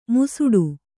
♪ musuḍu